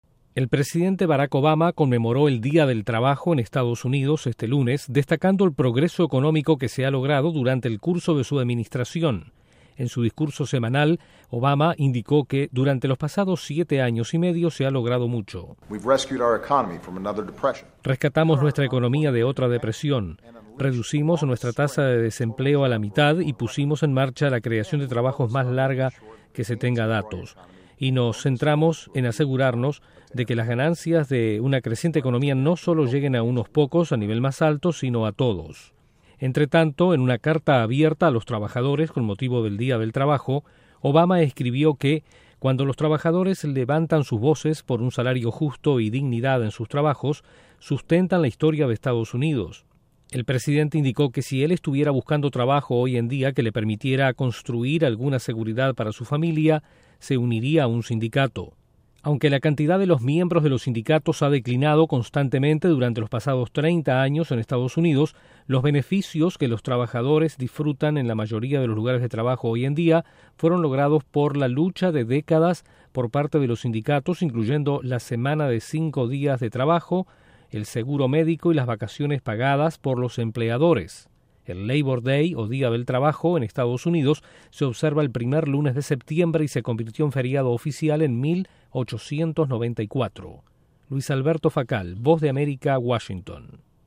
El presidente Barack Obama destaca sus logros económicos al conmemorarse este lunes el Día del Trabajo en Estados Unidos. Desde la Voz de América en Washington informa